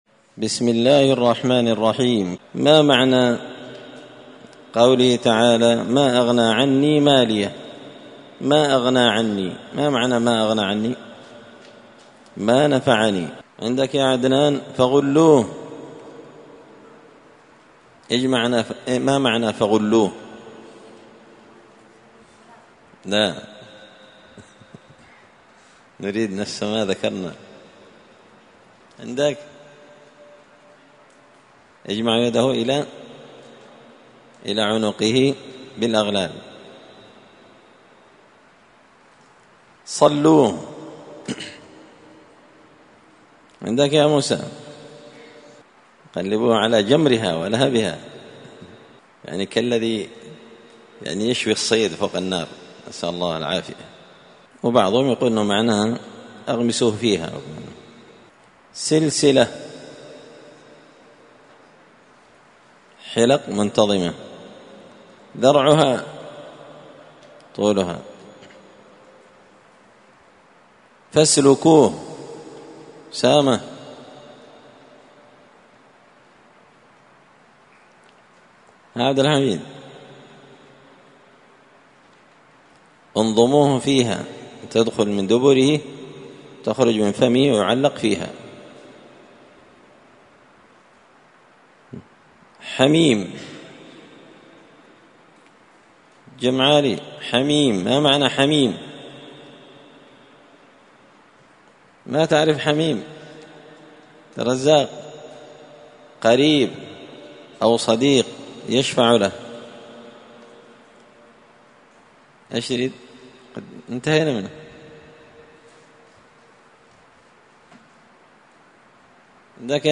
(جزء تبارك سورة الحاقة الدرس 73)